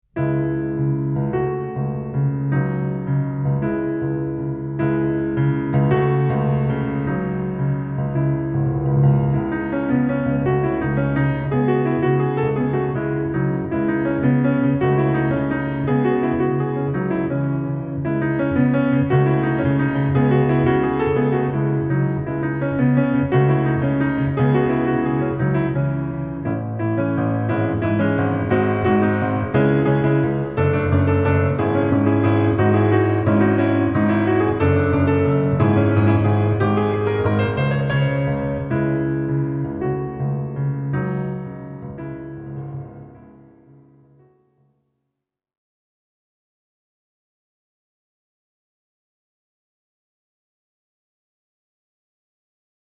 ピアノだけの音源です。